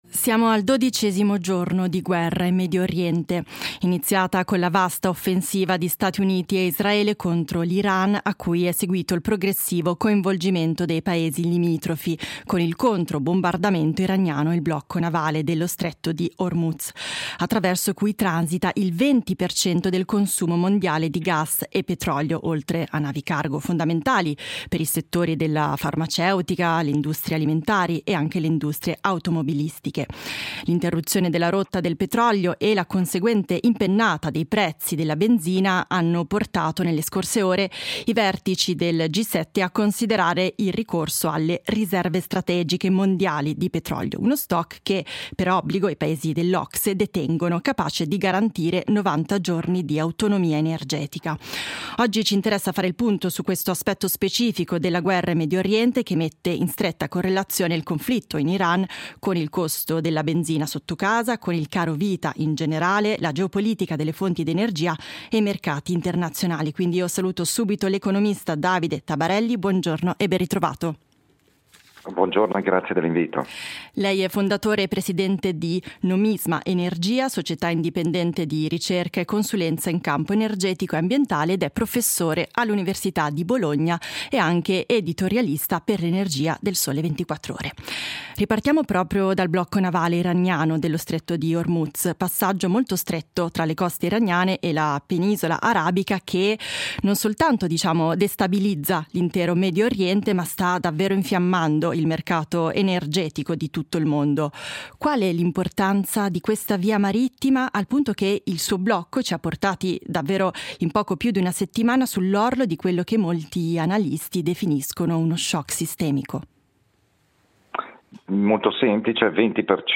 Si discute da due giorni il possibile rilascio collettivo di 300 o 400 milioni di barili, per rassicurare mercati e operatori economici e scongiurare le ripercussioni del caro-petrolio. Ne parliamo con l’economista